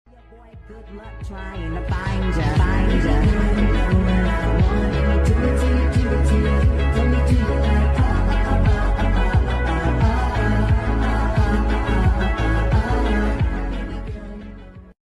Forgot to say its sped up